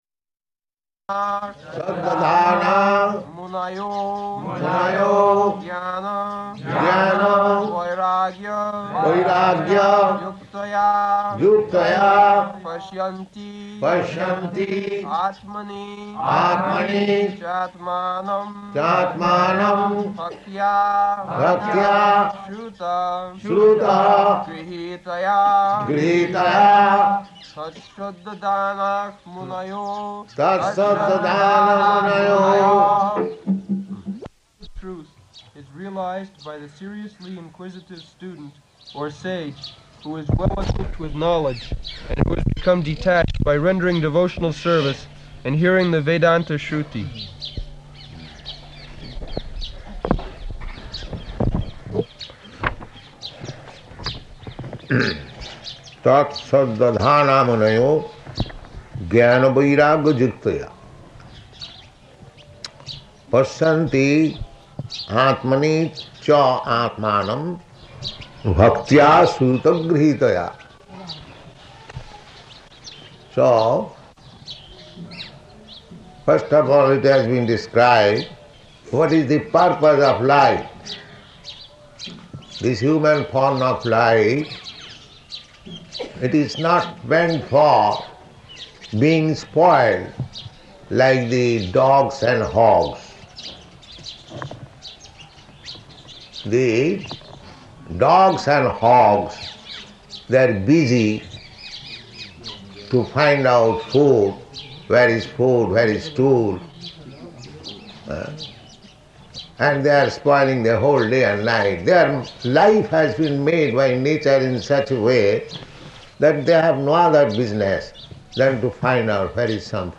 Location: Vṛndāvana
[Prabhupāda and devotees repeat]